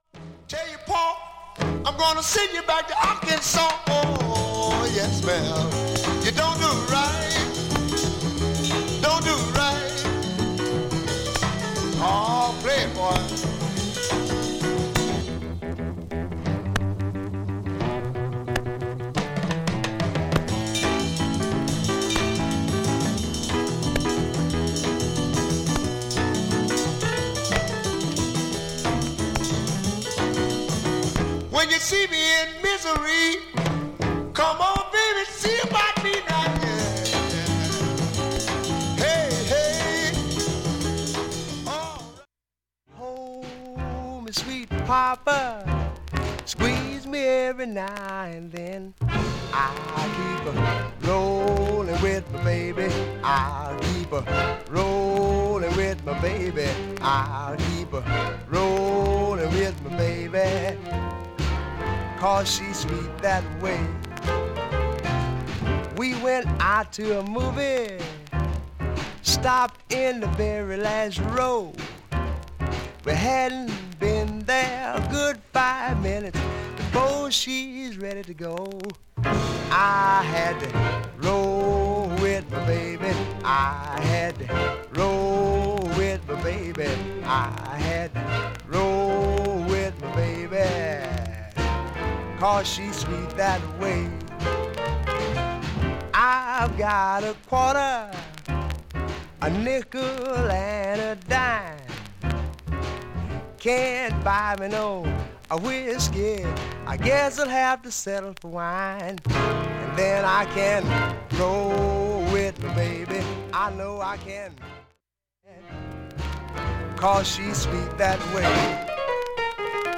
バックチリはほとんどありません
プツ出る箇所もありますがプツ自体は
かすかでストレスありません。
SHURE M 44G 針圧３グラムで
スレで40秒の間周回ポツ出ます。
周回プツ出ますがかすかです。
バックチリ出ますが問題無いレベル。
Mono, Black Label/DG